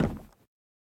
Minecraft Version Minecraft Version latest Latest Release | Latest Snapshot latest / assets / minecraft / sounds / block / chiseled_bookshelf / step3.ogg Compare With Compare With Latest Release | Latest Snapshot
step3.ogg